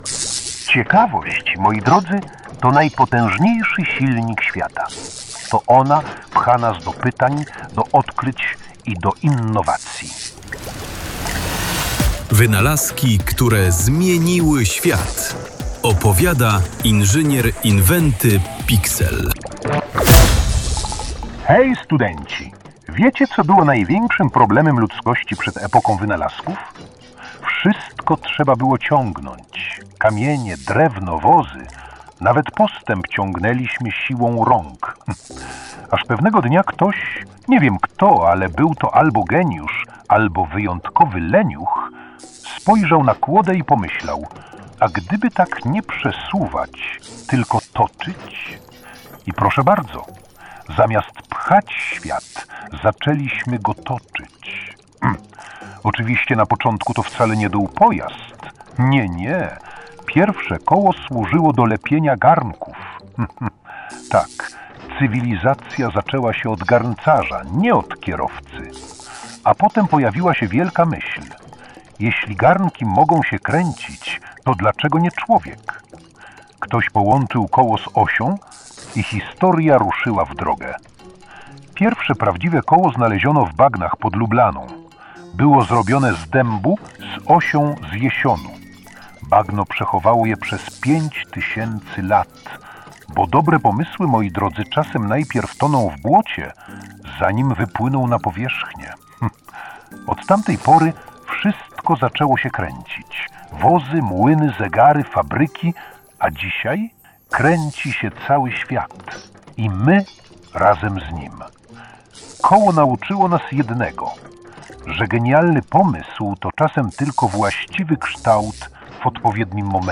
Narratorem cyklu jest ekscentryczny inżynier Inwenty Piksel, który z błyskiem w oku łączy świat nauki, fantazji i technologii.
Cykl zrealizowano z wykorzystaniem sztucznej inteligencji, dzięki czemu bohater – inżynier Inwenty Piksel – zyskał unikalny, ciepły i pełen energii głos .